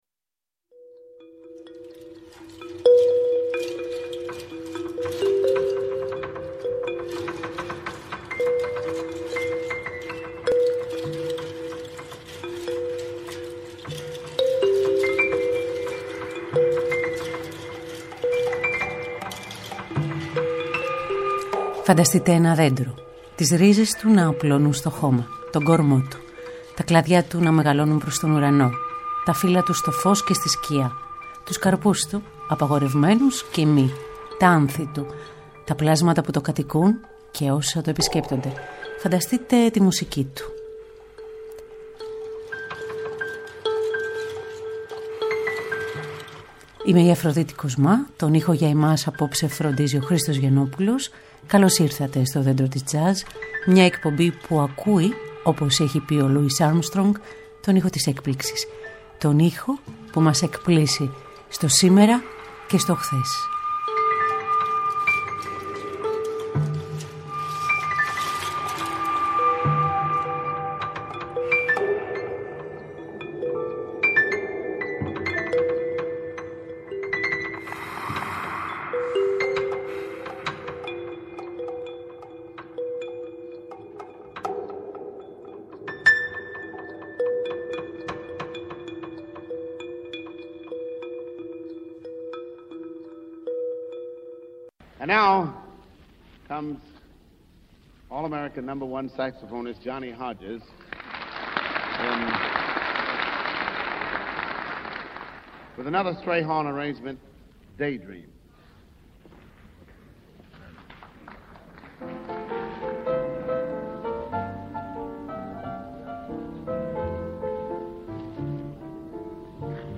ροκ ενέργεια και τζαζ αυτοσχεδιασμό
Μουσική Τζαζ